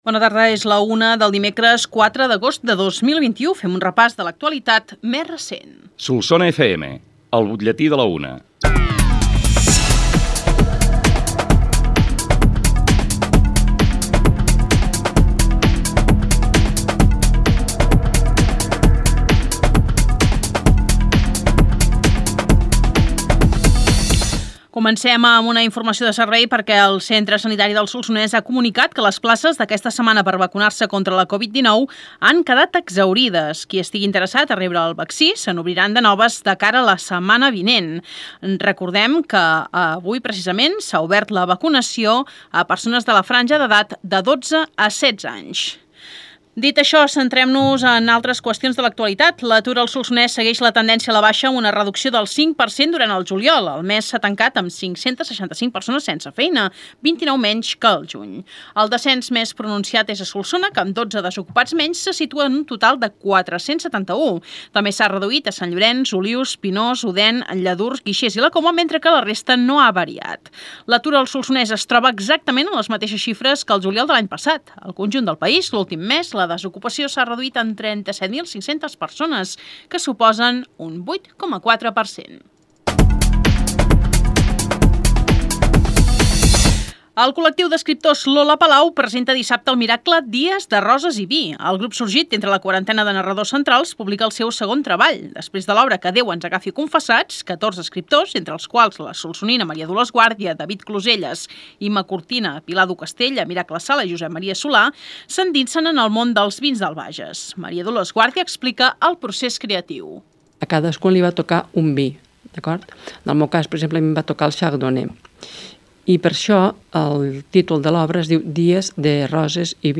Informatius: butlletí de la 1 - Solsona FM, 2021